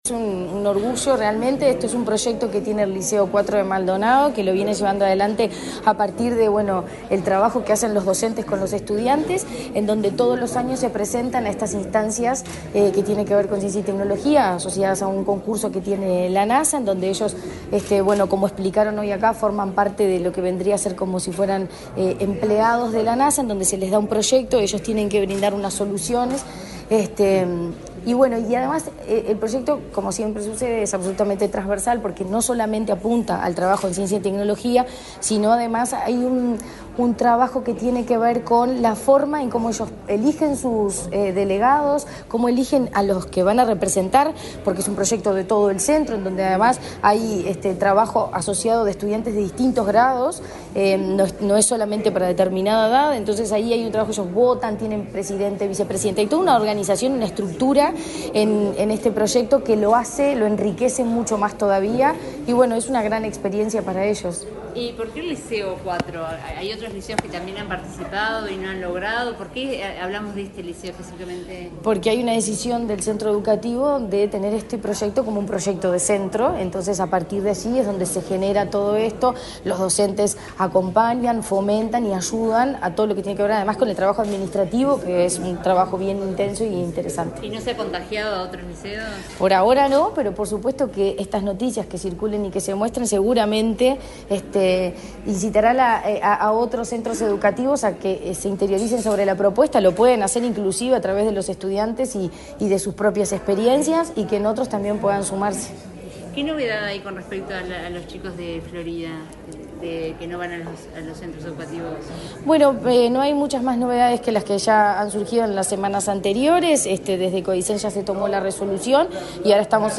Declaraciones de la presidenta de ANEP, Virginia Cáceres
La presidenta de la Administración Nacional de Educación Pública (ANEP), Virginia Cáceres, dialogó con la prensa, luego de participar del acto en el